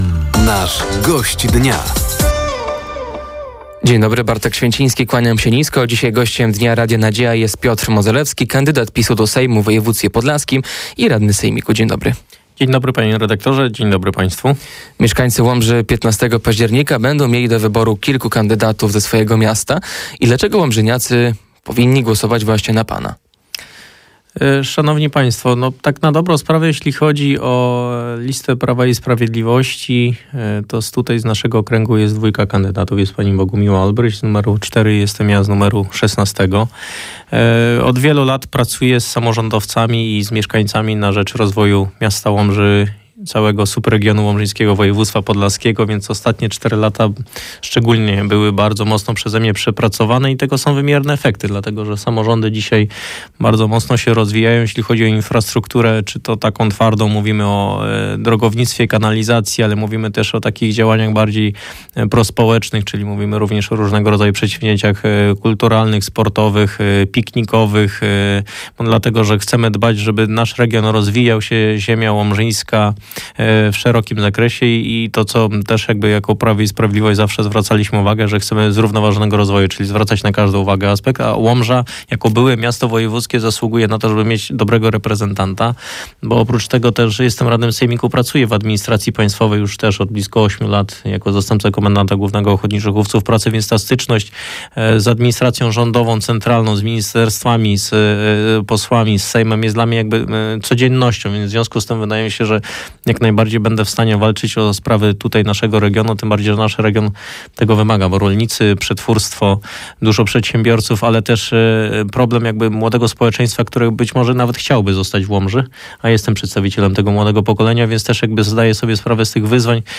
Gościem Dnia Radia Nadzieja był radny sejmiku województwa podlaskiego i kandydat PiS-u do sejmu, Piotr Modzelewski. Tematem rozmowy była trwająca kampania wyborcza i pomysł radnego na rozwój Łomży.